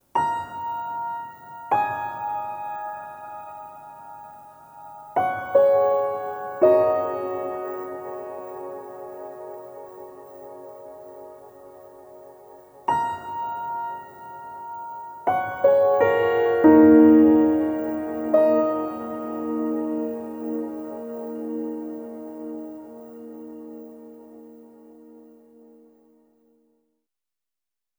Treated Piano 03.wav